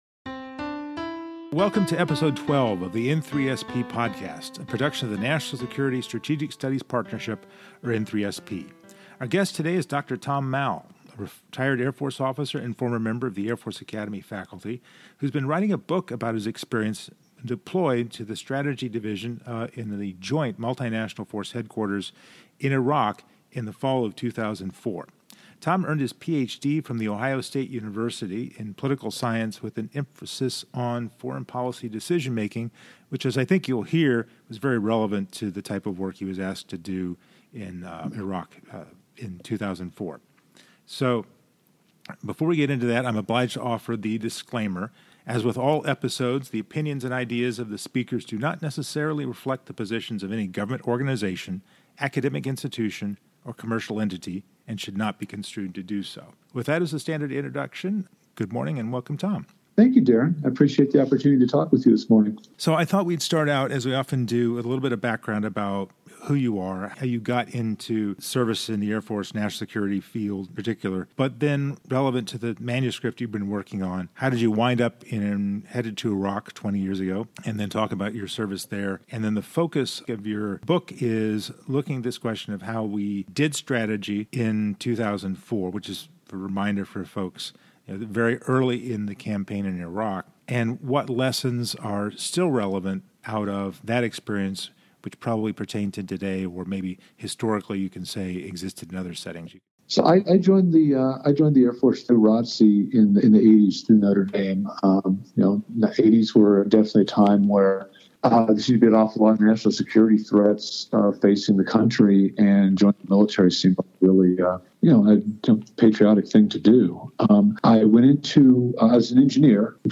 The N3SP Podcast is a series of conversations on national security topics and careers with experts from universities, government organizations, and the private sector which are part of the overall National Security Strategic Studies Partnership.